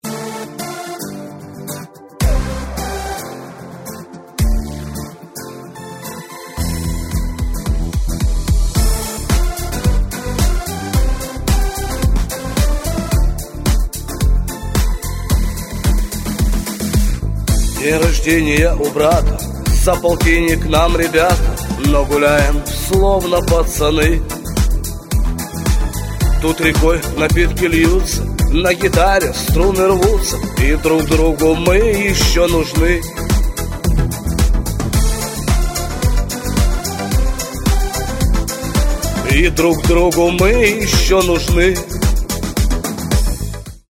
• Качество: 128, Stereo
громкие
шансон